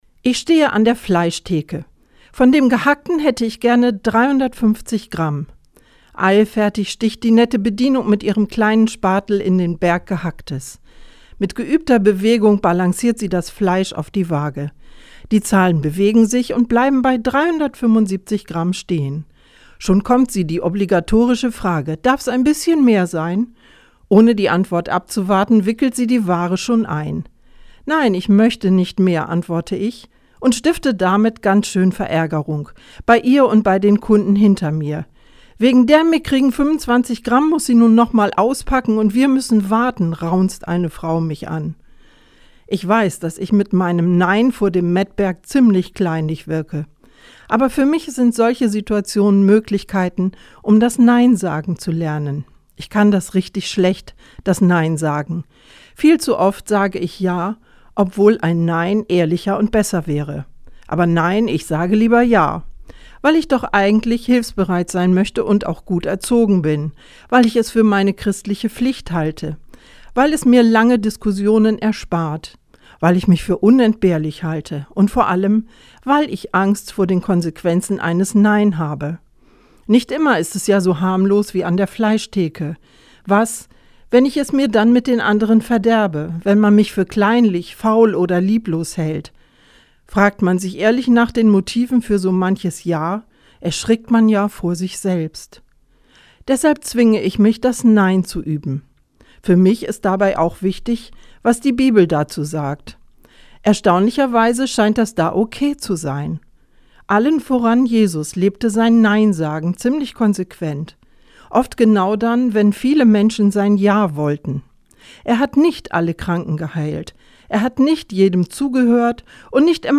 Radioandacht vom 27. Juli